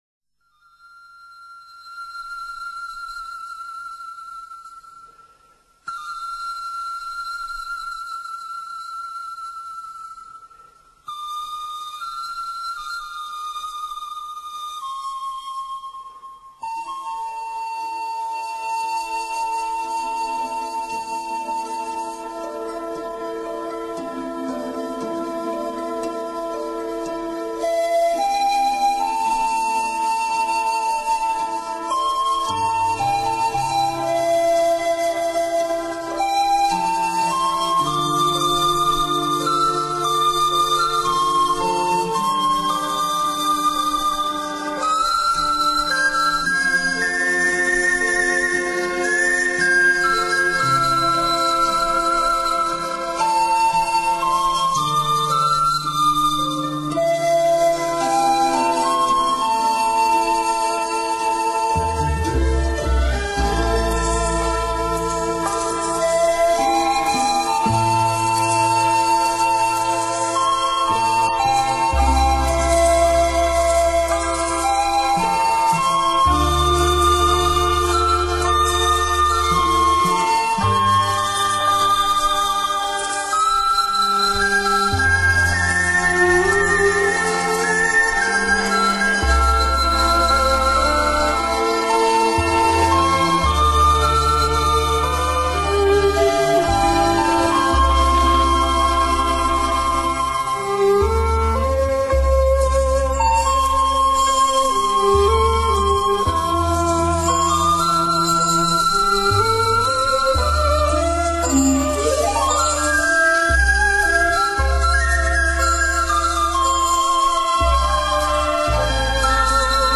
音乐类型：民乐
音乐清幽、飘逸、空灵富有浓浓的诗意，音中生韵，韵又有情，情为感而发。
伴奏音乐也很纯净，听起来让人感觉很清爽，犹如来自天堂的声音，净化我们的心灵。
这首乐曲的灵魂，所有的吹奏乐器都不如排箫表现的如此到位，简直是出神入化。